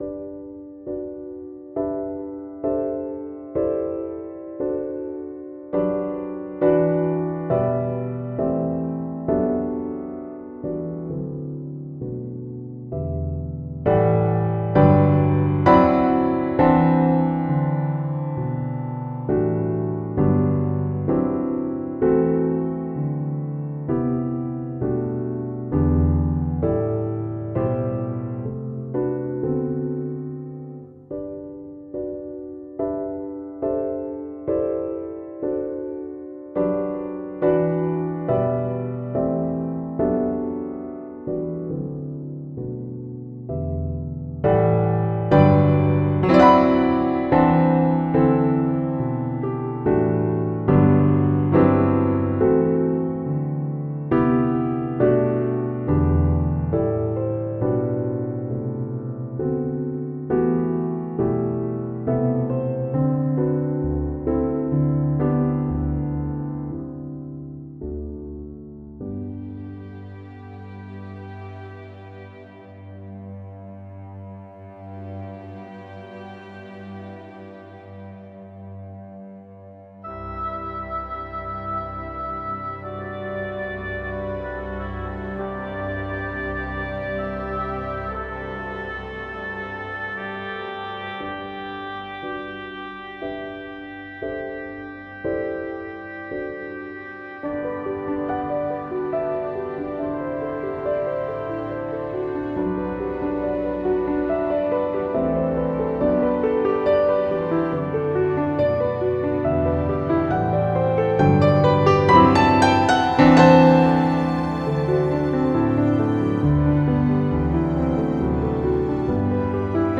Piano and chamber orchestra.